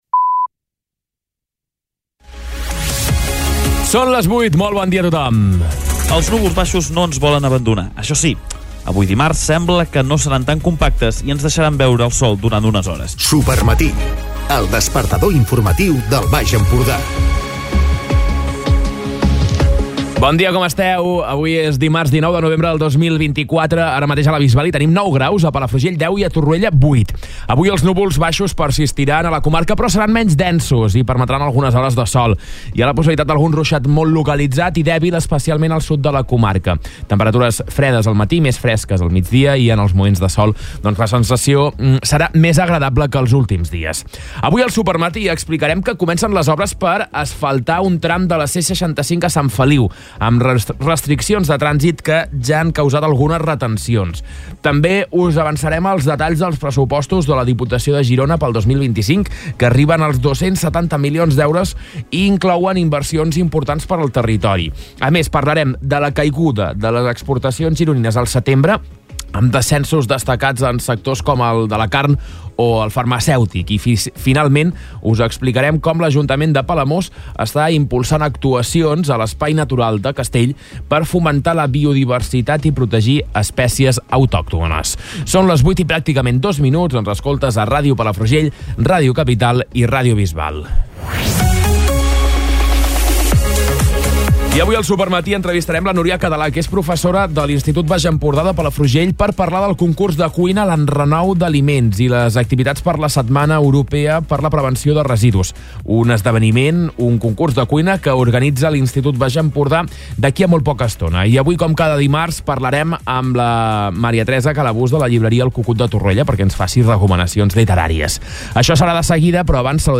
Escolta l'informatiu d'aquest dimarts